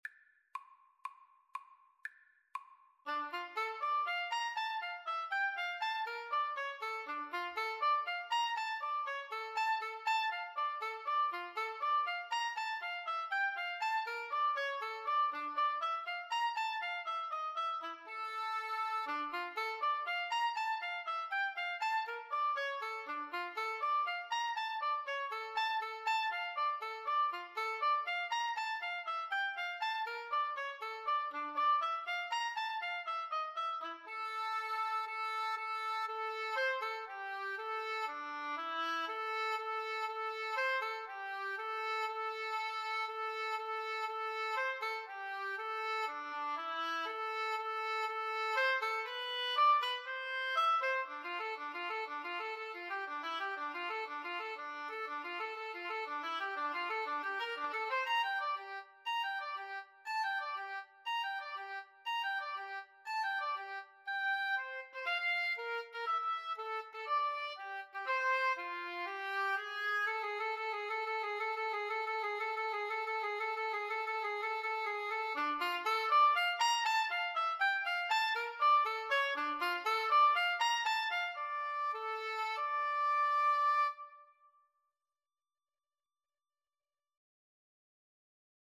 Oboe Duet version
2/2 (View more 2/2 Music)
Allegro (View more music marked Allegro)
Oboe Duet  (View more Advanced Oboe Duet Music)
Classical (View more Classical Oboe Duet Music)